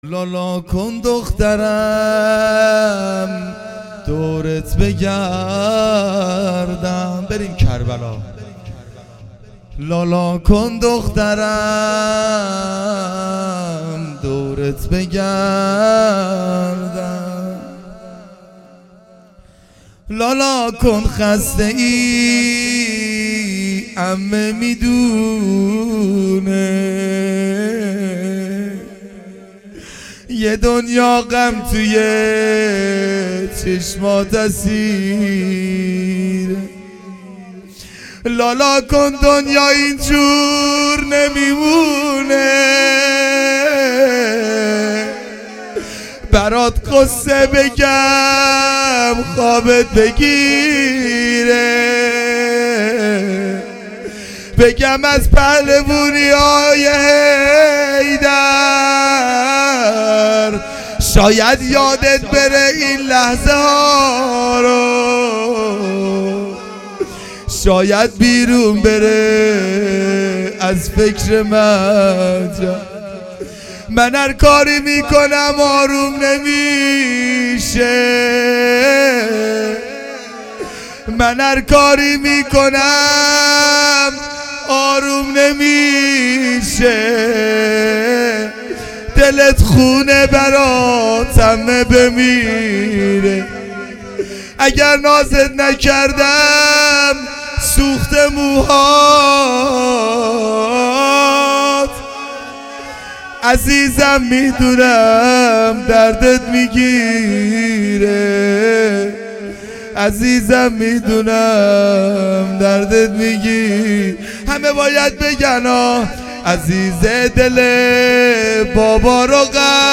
روضه سوزناک حضرت رقیه س
گزارش صوت مراسم هفتگی